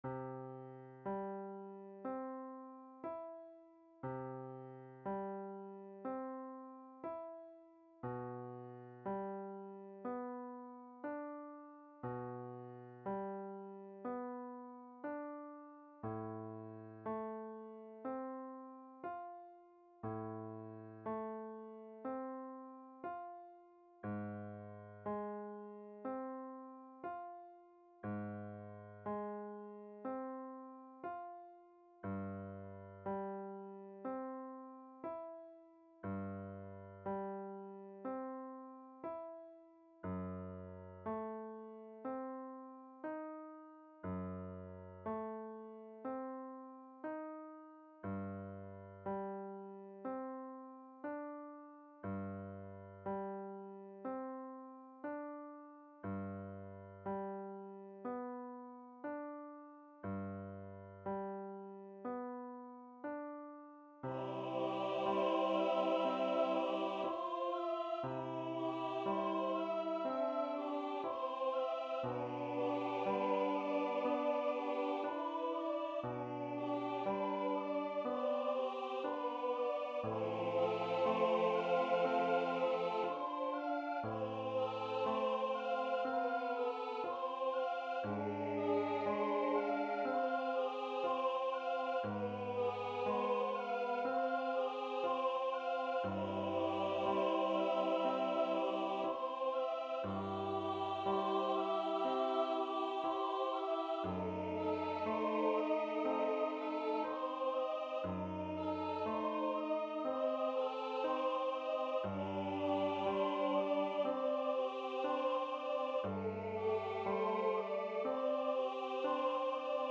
Piano and vocal